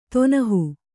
♪ tonahu